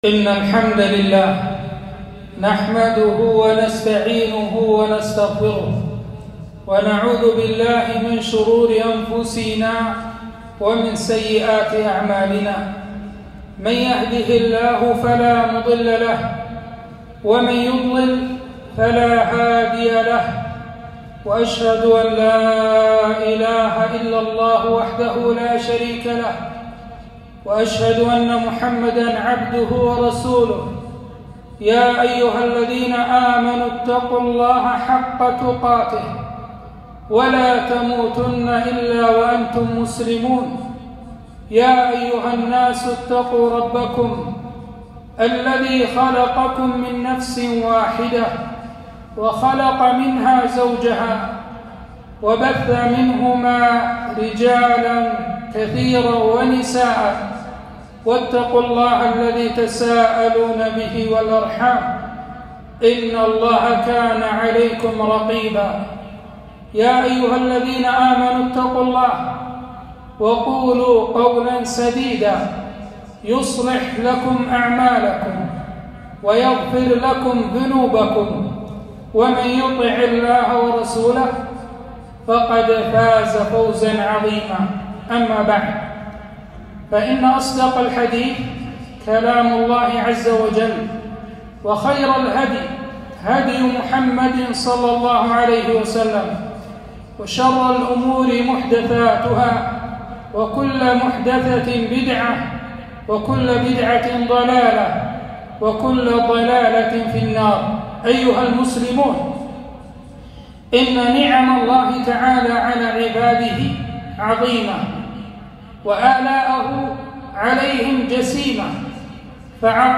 خطبة - بالشكر تدوم النعم